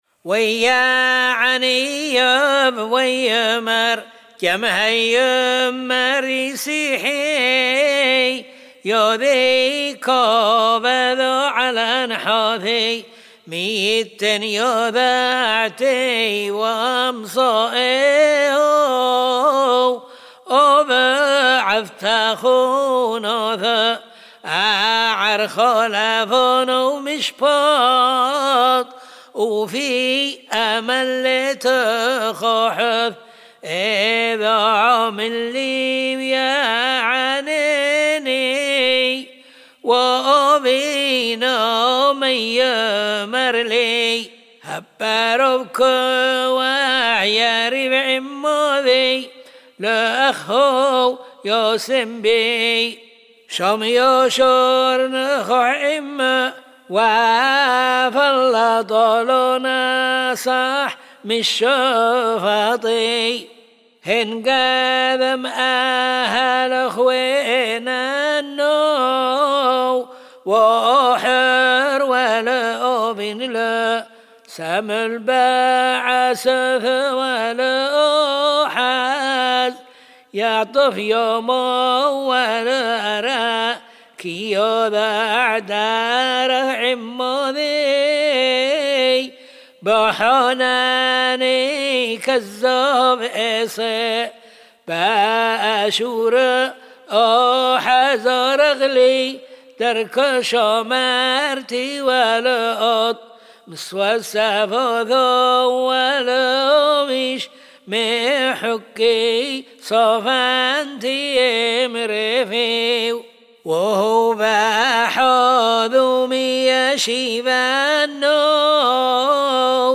לכן תהנו מהמנגינה.